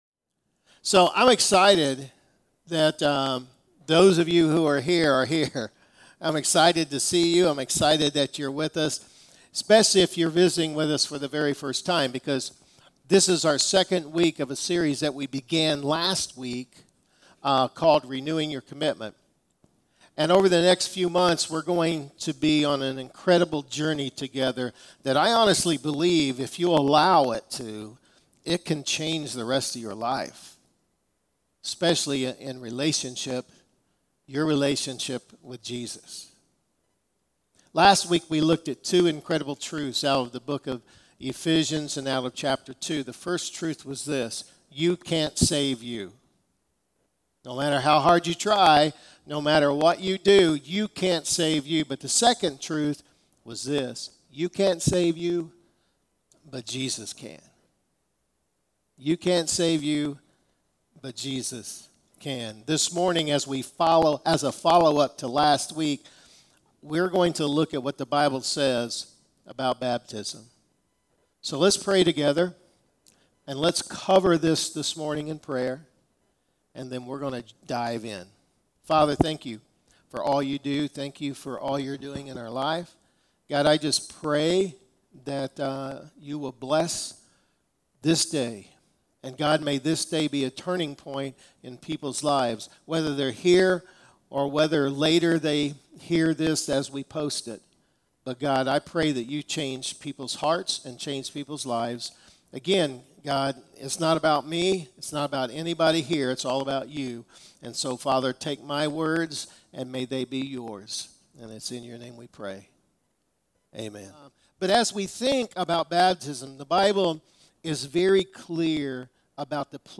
Sermons | Campbellsville Christian Church